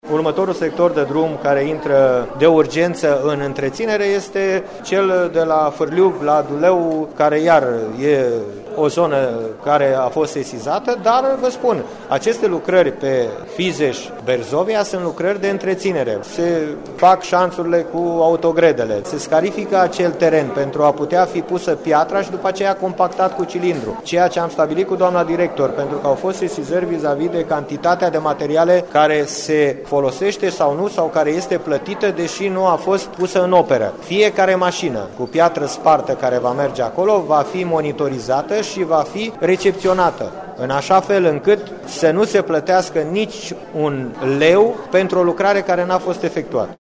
În această perioadă se fac lucrări de întreţinere pe unele tronsoane grav afectate. Vicepreşedintele Forului Judeţean, Ilie Iova, spune că toate decontările efectuate de Direcția de Administrare a Domeniului Public și Privat Caraș-Severin vor fi atent verificate.